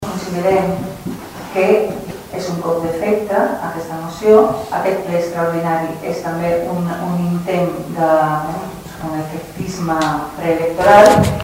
Maite Bravo (Iniciativa-Verds-Alternativa) va titllar la moció d’electoralista.